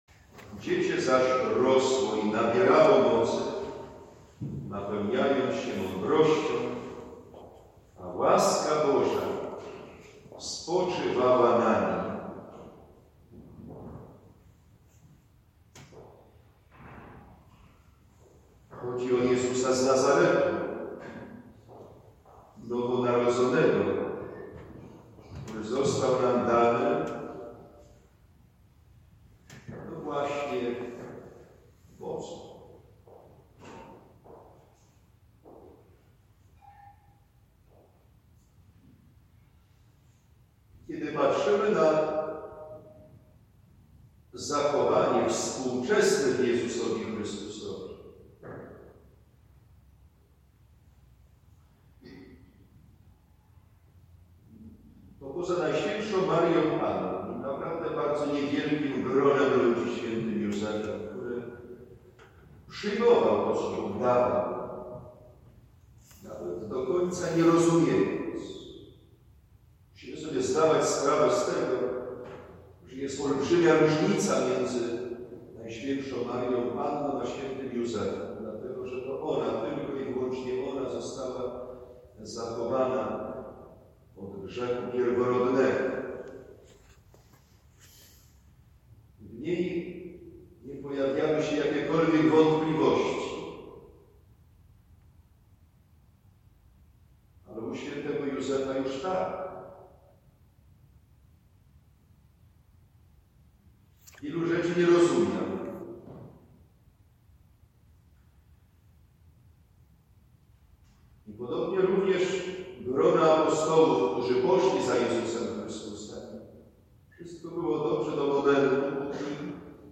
homilia